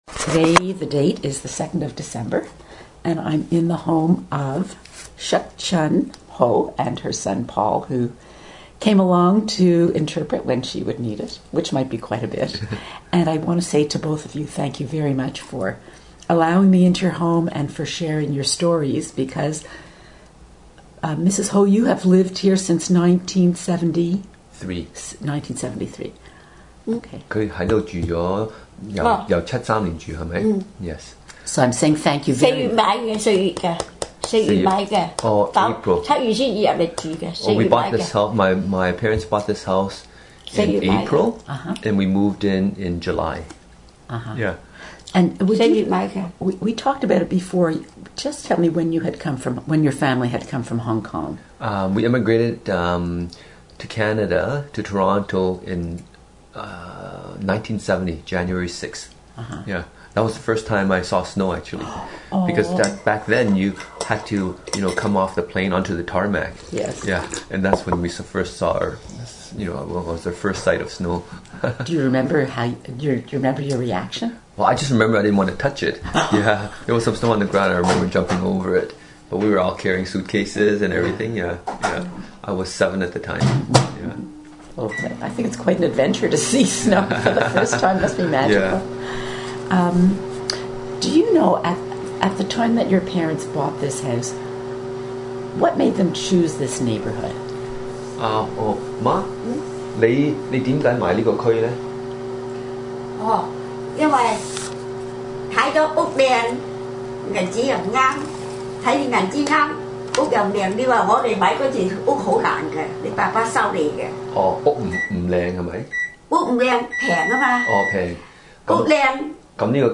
Interview TRANSCRIPT Interview INDEX Direct download of MP3 File Press the triangle symbol to play.